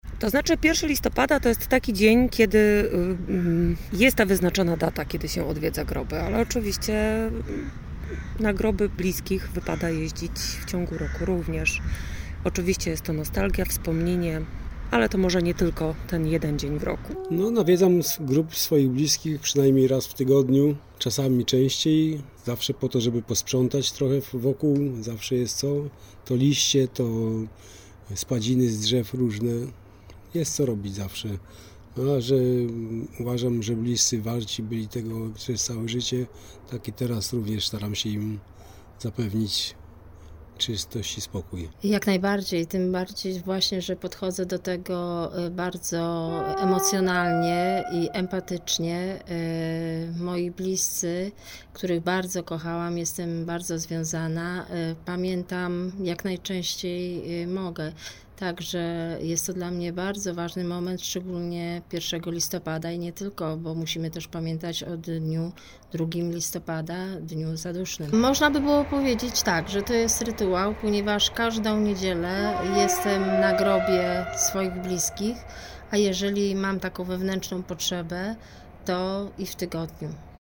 Zaglądamy na parafialny cmentarz św. Jacka na wrocławskich Swojczycach, pytając wrocławianki i wrocławian o to jak zmieniają się cmentarze.